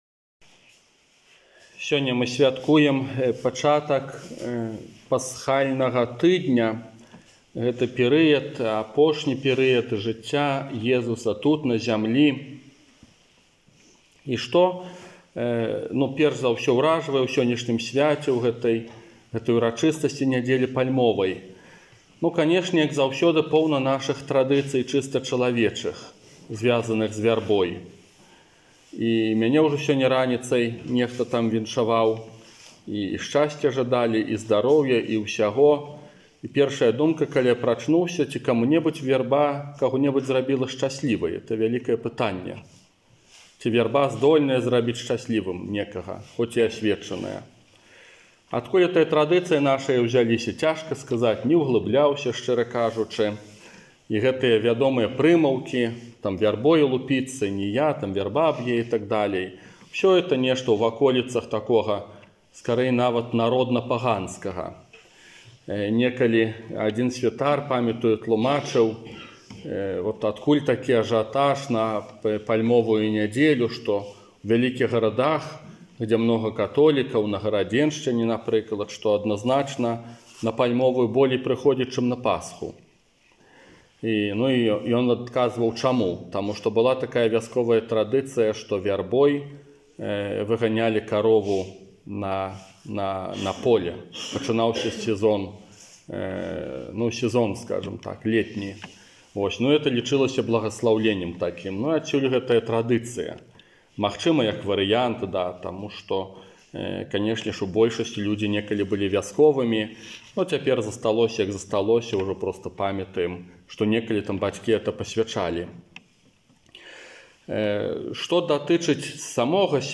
ОРША - ПАРАФІЯ СВЯТОГА ЯЗЭПА
Казанне на Пасхальную Вігілію